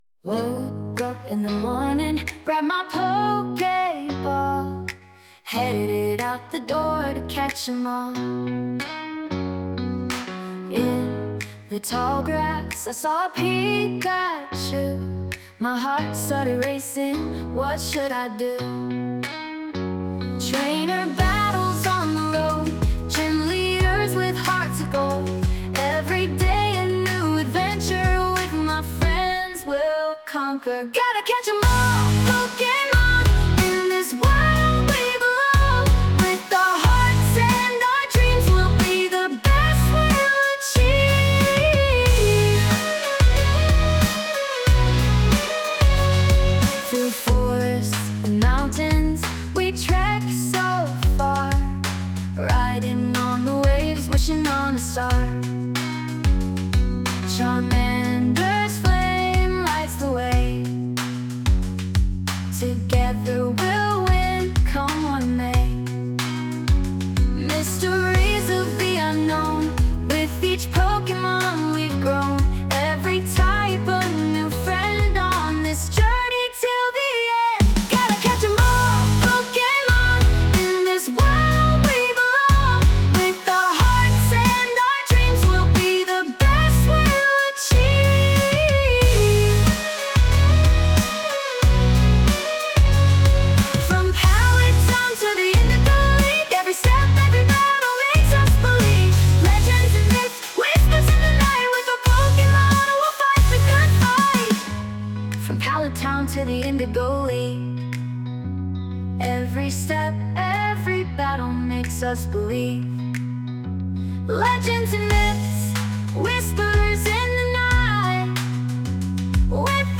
fake_song.mp3